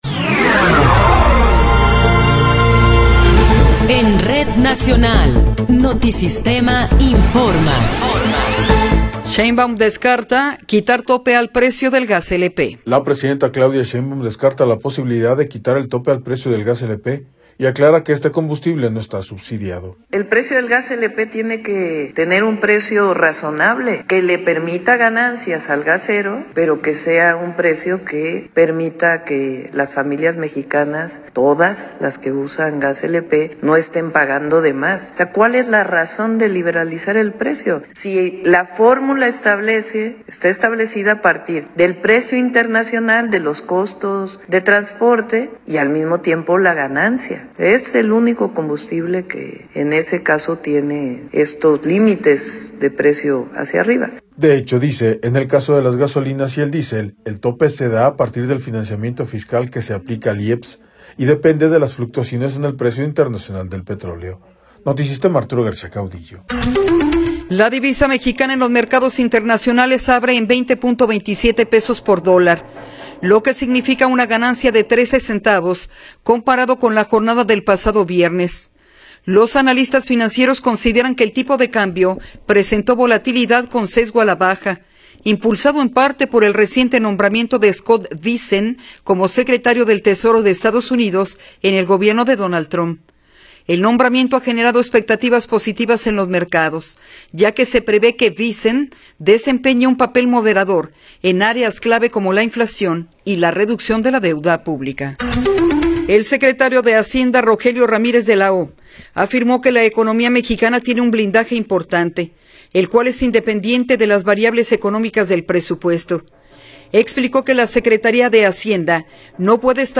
Noticiero 10 hrs. – 25 de Noviembre de 2024